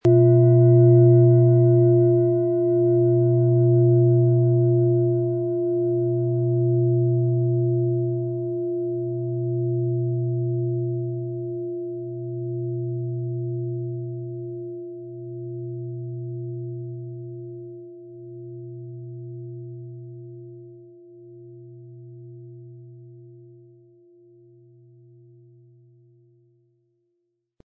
• Mittlerer Ton: Platonisches Jahr
Ein unpersönlicher Ton.
Im Sound-Player - Jetzt reinhören hören Sie den Original-Ton dieser Schale.
Aber uns würde der kraftvolle Klang und diese außerordentliche Klangschwingung der überlieferten Fertigung fehlen.
MaterialBronze